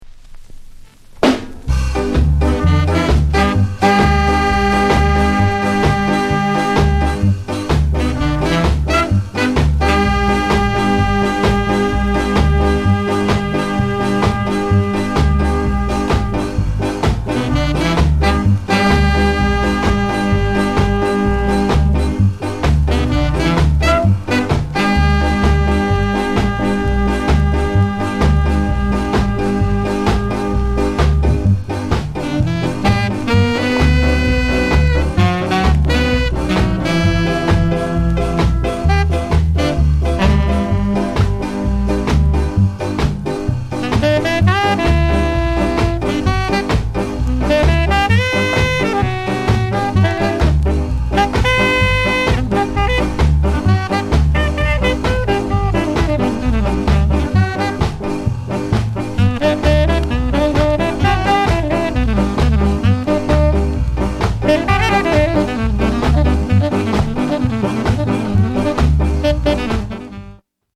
NICE SKA VOCAL